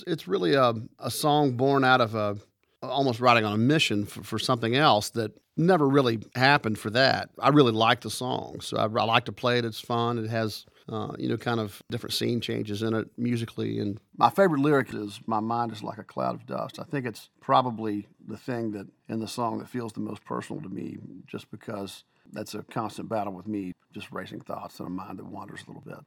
Chris Stapleton talks about "White Horse."